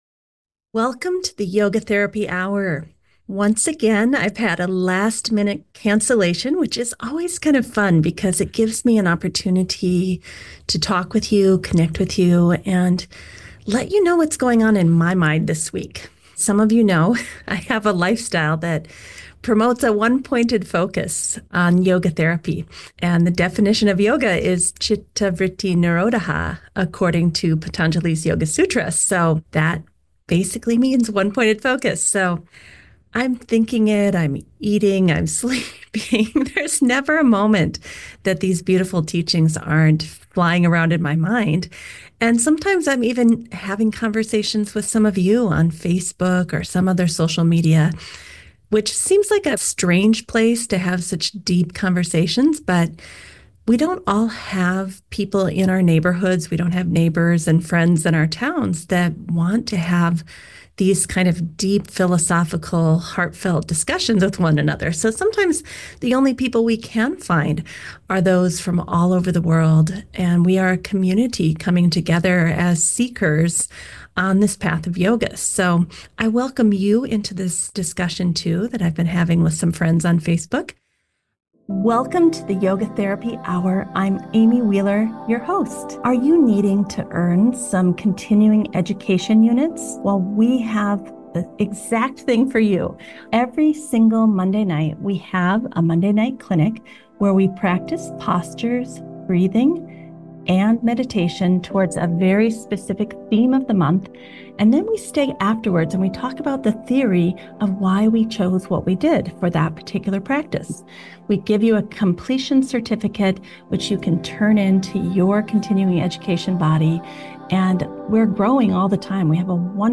Opening Paragraph In this solo episode